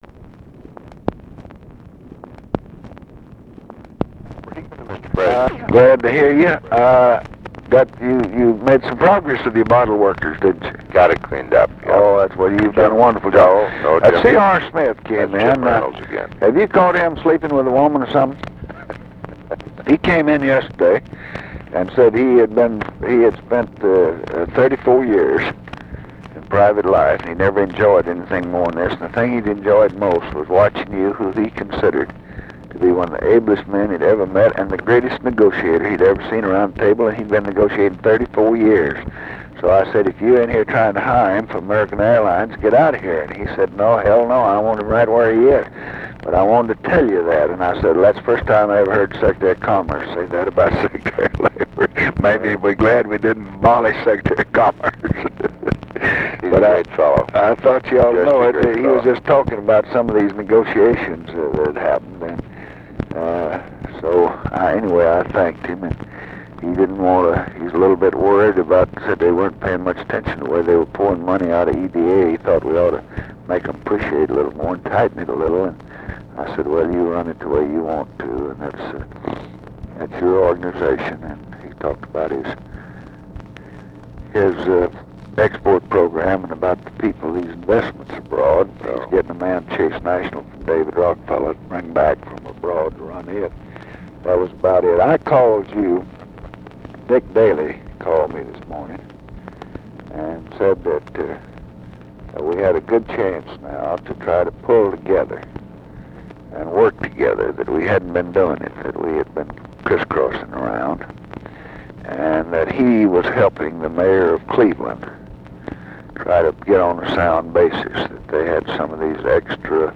Conversation with WILLARD WIRTZ and OFFICE CONVERSATION, March 23, 1968
Secret White House Tapes